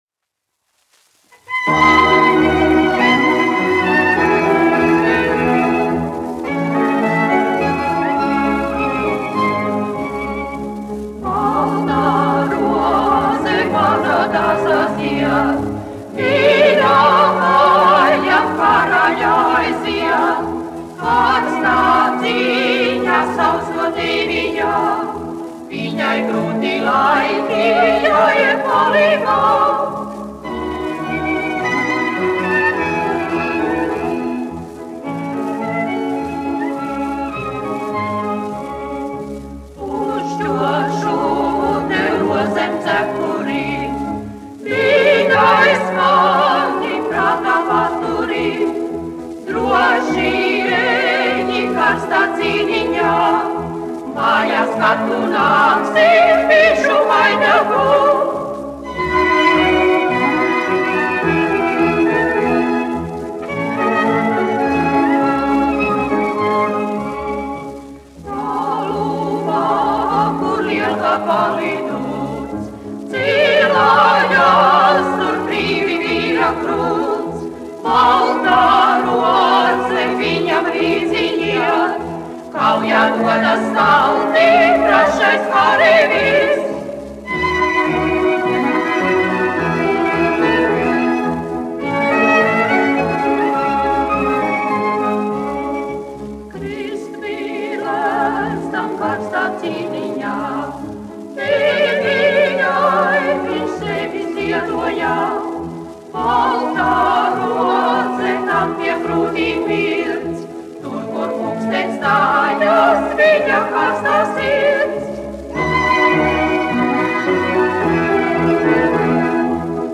1 skpl. : analogs, 78 apgr/min, mono ; 25 cm
Vokālie kvarteti ar orķestri
Latvijas vēsturiskie šellaka skaņuplašu ieraksti (Kolekcija)